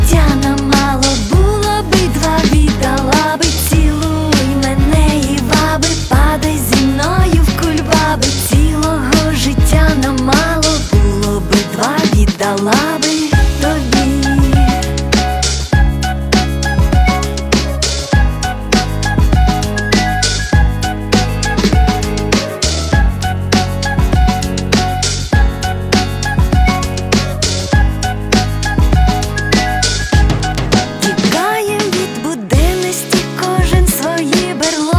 Жанр: Русская поп-музыка / Поп / Русский рок / Русские